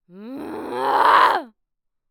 Female_Medium_Growl_01.wav